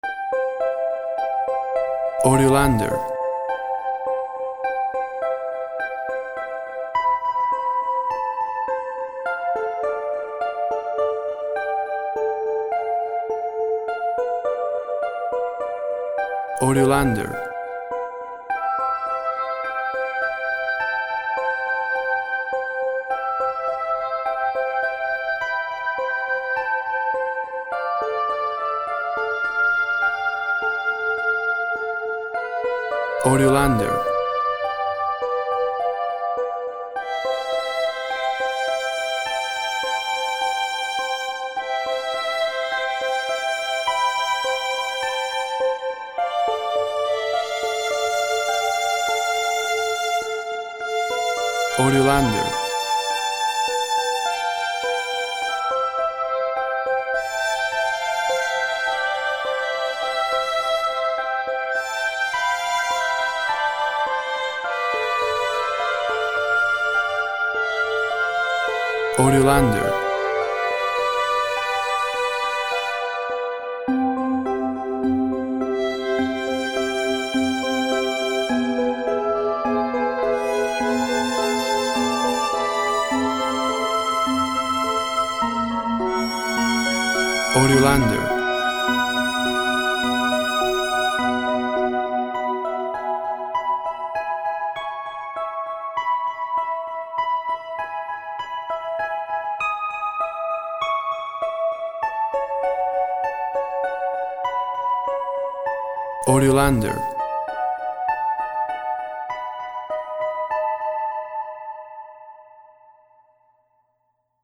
Innocent harp, reed, and violin instrumental.
Tempo (BPM) 104